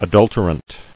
[a·dul·ter·ant]